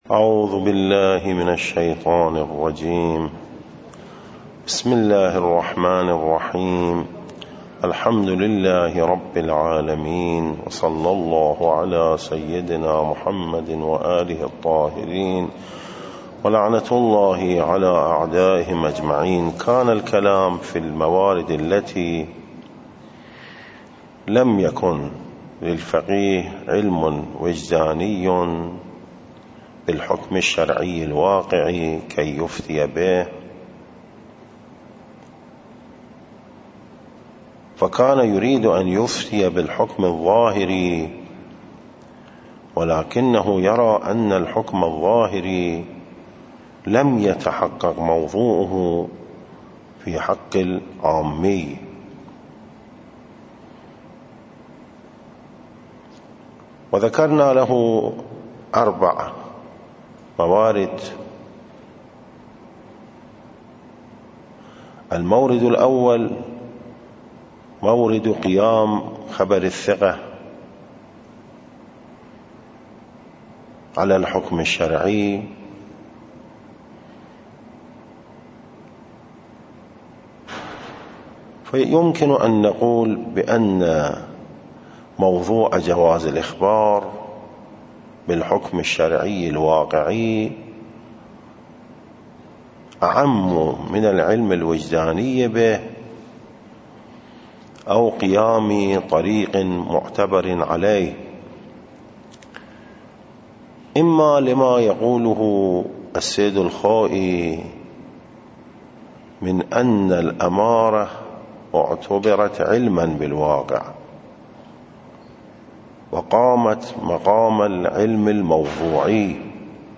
الدرس 72 (العربیه)